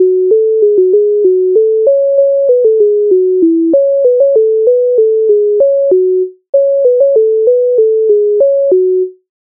MIDI файл завантажено в тональності fis-moll
Піду в садочок Українська народна пісня з обробок Леонтовича с. 151
Ukrainska_narodna_pisnia_Pidu_v_sadochok.mp3